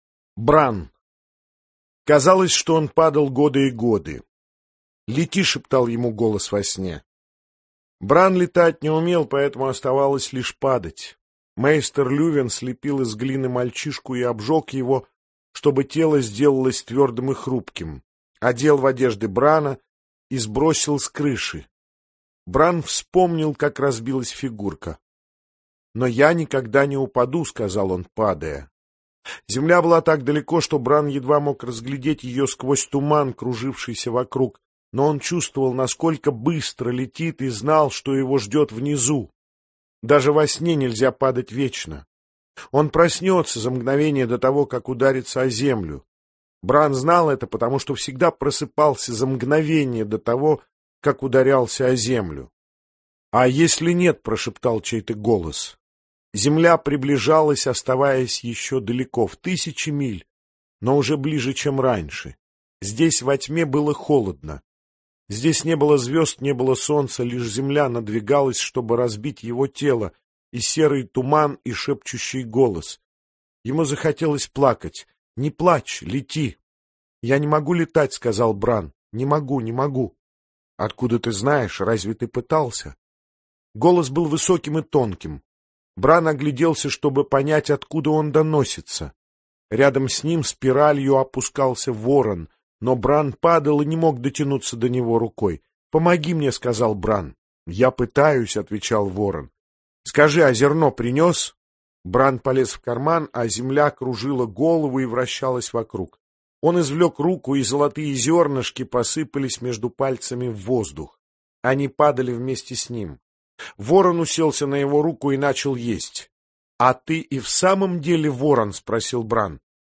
Аудиокнига Игра престолов - купить, скачать и слушать онлайн | КнигоПоиск